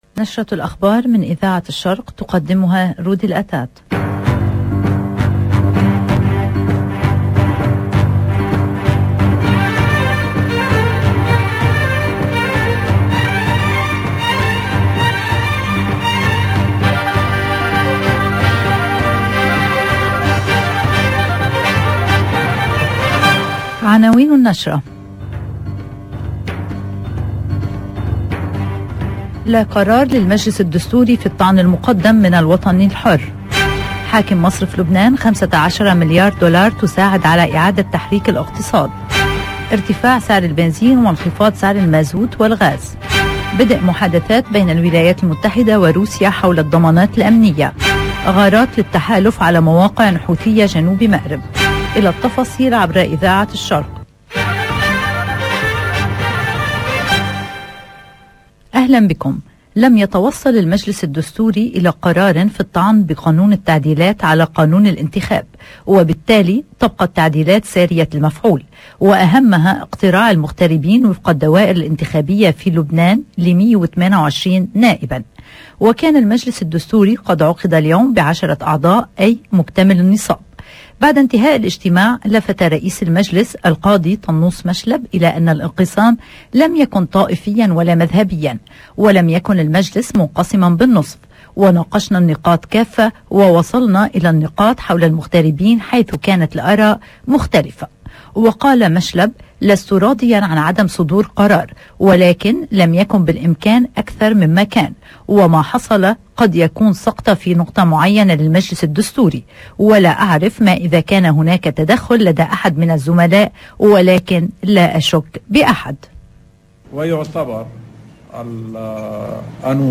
LE JOURNAL DU LIBAN DE 13 H30 EN LANGUE ARABE DU 21/12/2021